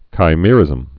(kī-mîrĭzəm, kīmə-rĭz-)